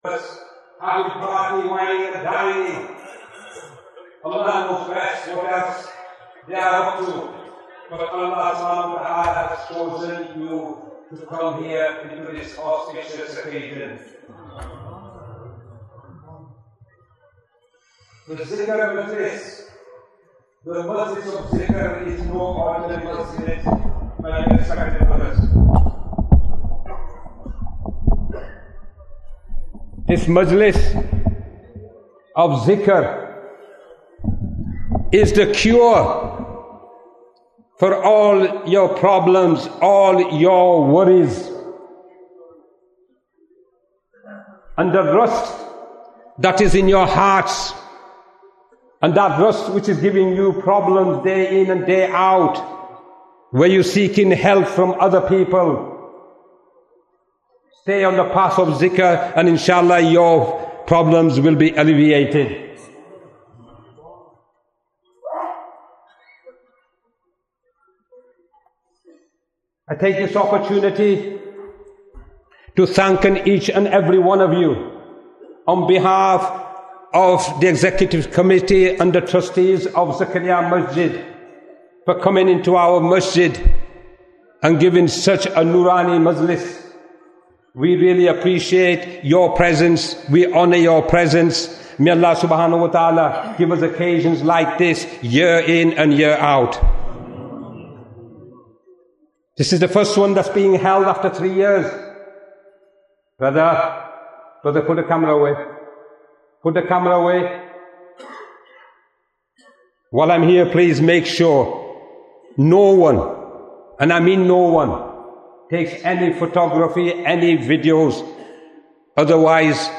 Ijtema Annual Majlis-e-Dhikr 2022 Programme Bayan, 57 minutes25th December, 2022